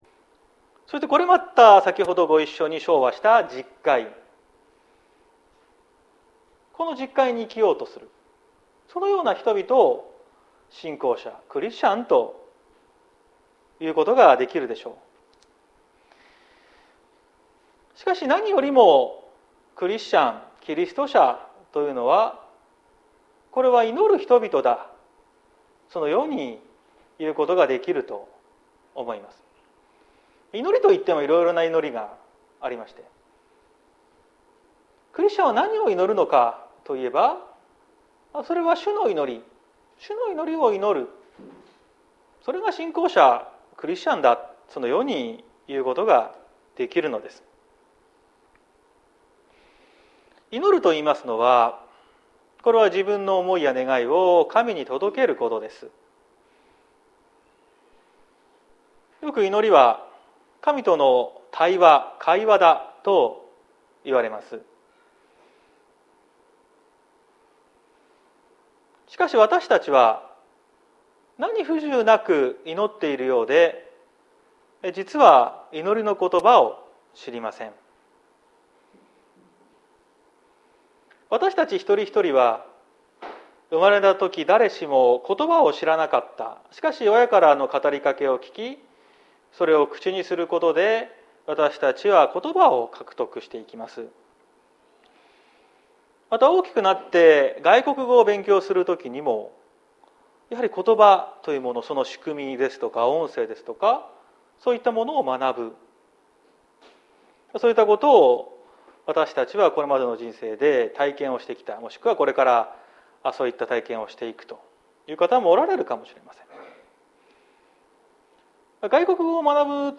2022年04月24日朝の礼拝「天の父よと祈る」綱島教会
綱島教会。説教アーカイブ。